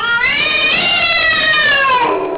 Cathowl
CATHOWL.wav